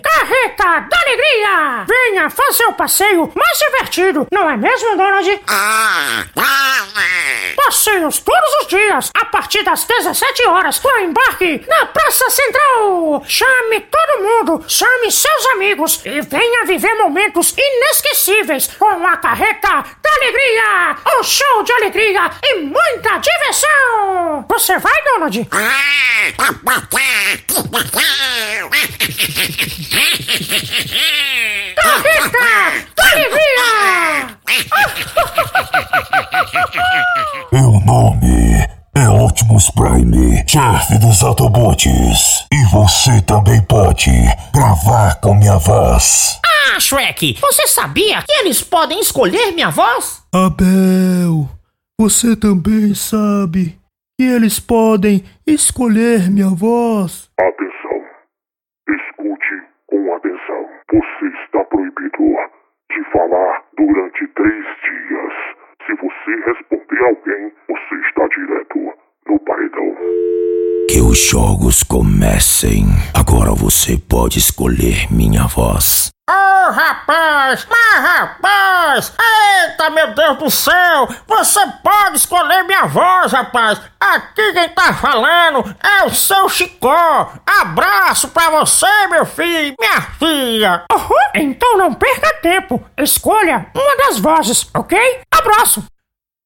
Vozes Caricatas: